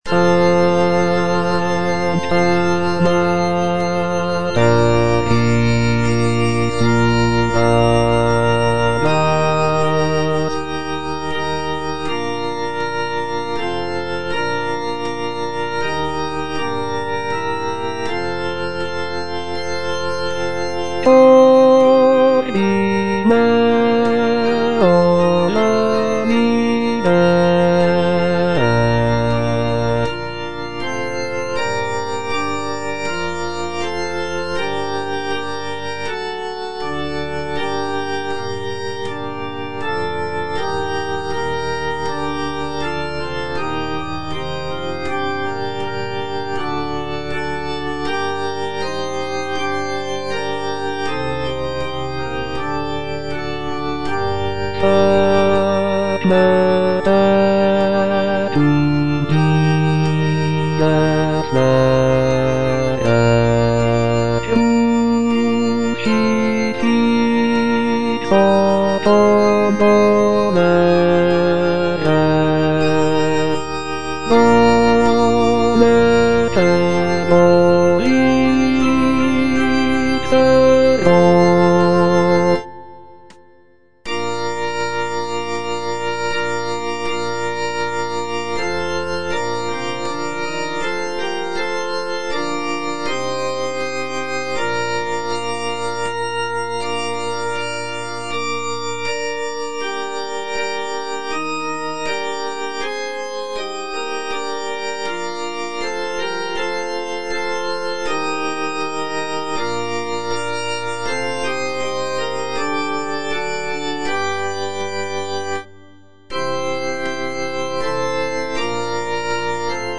Choralplayer playing Stabat Mater by G.P. da Palestrina based on the edition CPDL #48614
G.P. DA PALESTRINA - STABAT MATER Sancta Mater, istud agas (bass II) (Voice with metronome) Ads stop: auto-stop